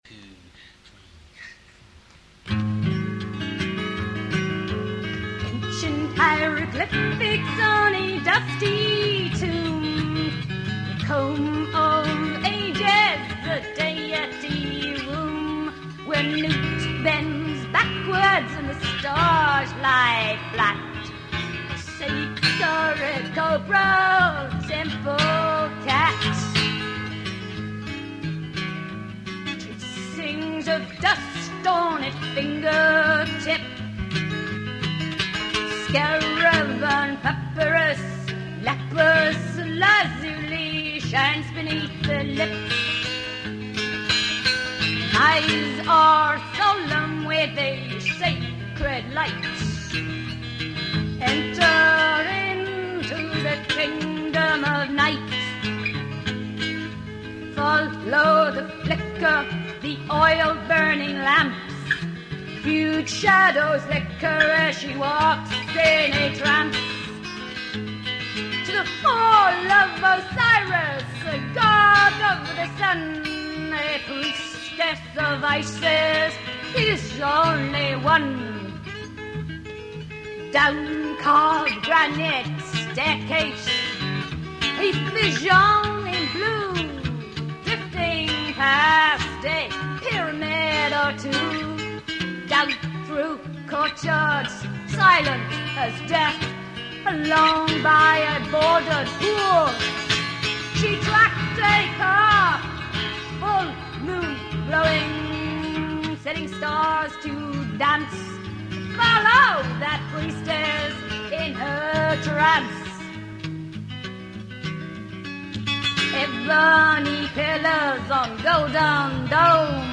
guitar recorded in theearly 1980's